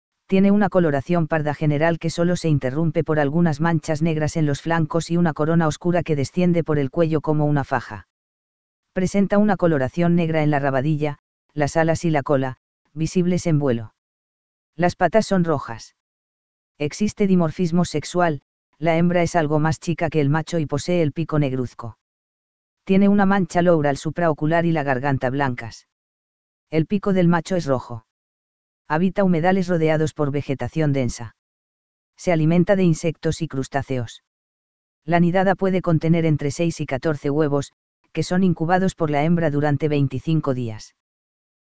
Amazonetta brasiliensis ipecutiri - Pato brasilero